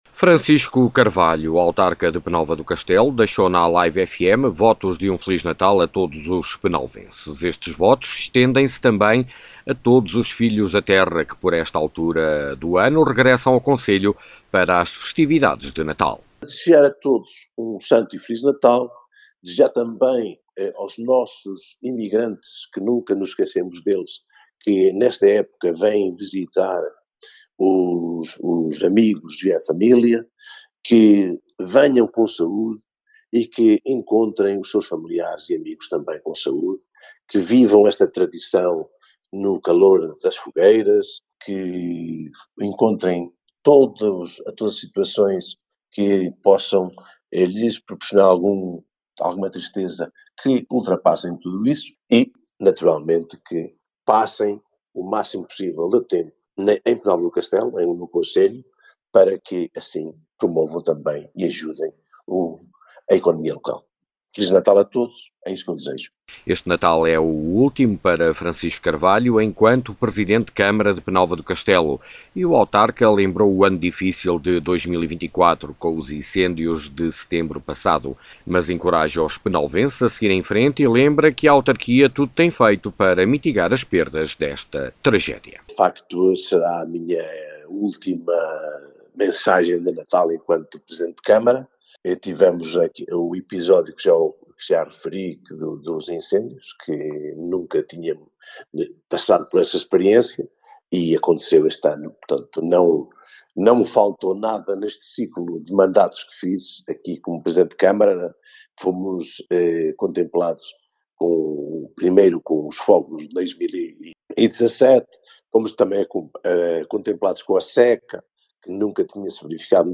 Francisco Carvalho, autarca de Penalva do Castelo deixa mensagem de Natal a todos os Penalvenses